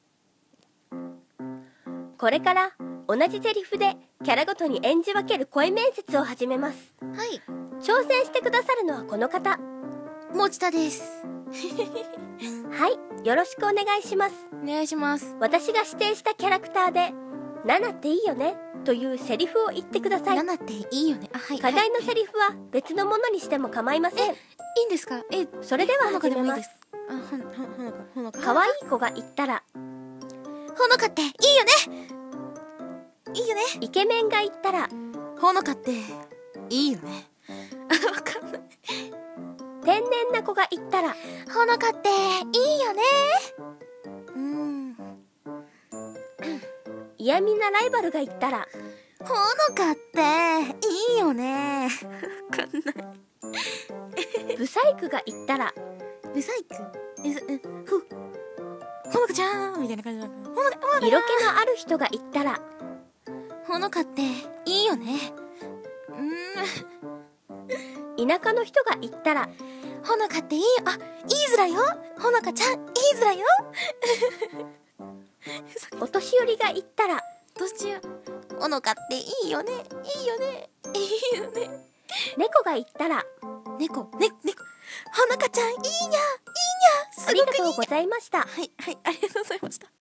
同じセリフでキャラごとに演じ分ける声面接